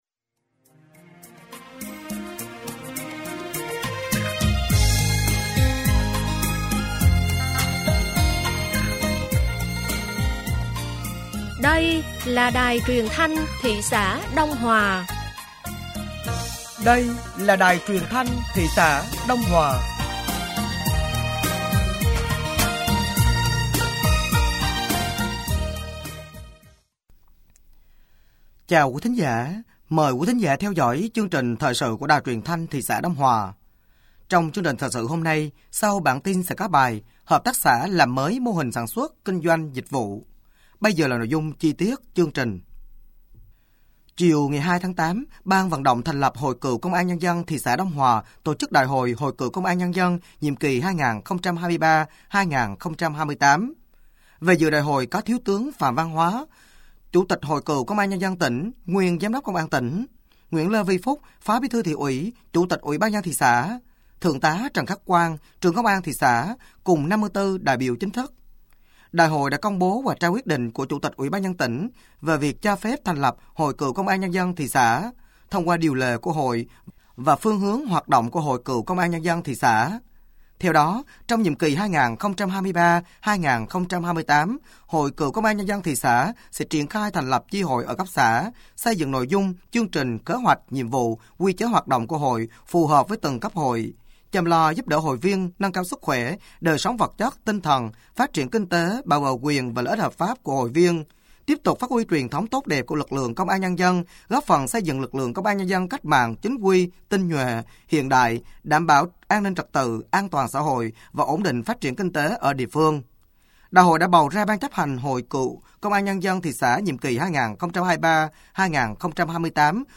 Thời sự tối ngày 02 và sáng ngày 03 tháng 8 năm 2024